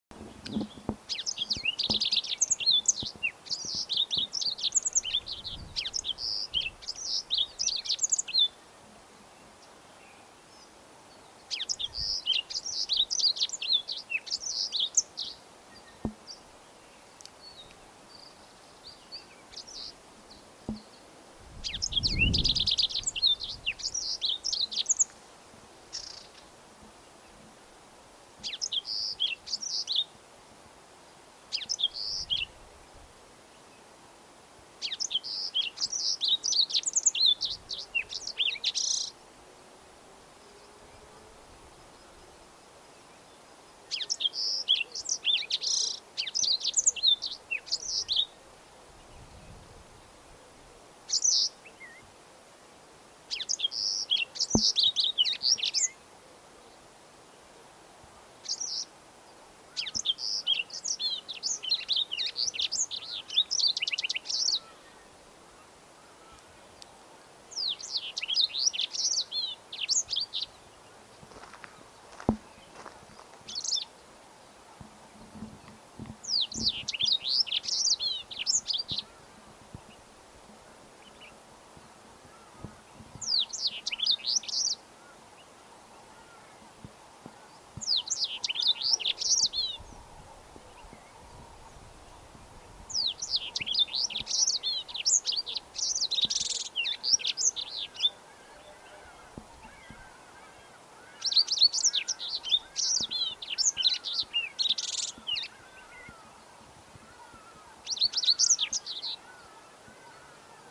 Kanarie geluid
Elk geluid, van heldere fluittonen tot zachte melodietjes, speelt een cruciale rol in hoe deze vogels communiceren en zich uiten.
Kanarie zang kenmerkt zich door een mix van ratels en trillers.
Deze geluiden klinken soms als brekend glas, wat uniek is voor de Europese kanarie.